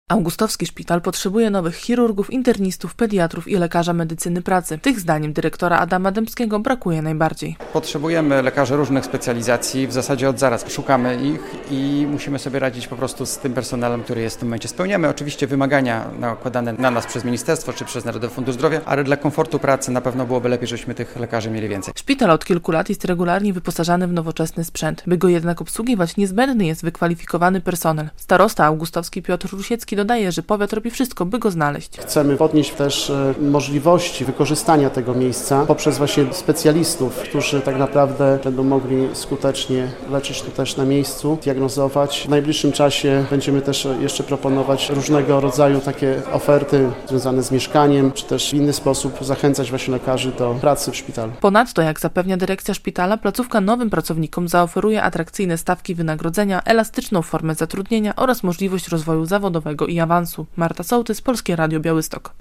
relacja
Starosta augustowski Piotr Rusiecki dodaje, że powiat robi wszystko by ich znaleźć.